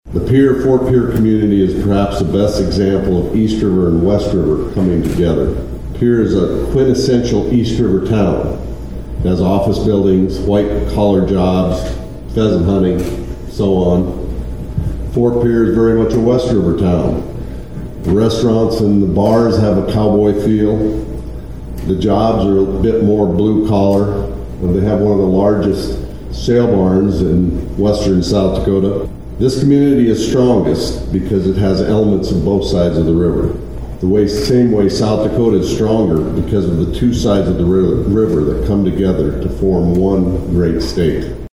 Various local, state and federal dignitaries gathered on Tuesday (March 25, 2025) to hold the official ribbon cutting ceremony for the new Lieutenant Commander John C. Waldron Memorial Bridge over the Missouri River between Fort Pierre and Pierre.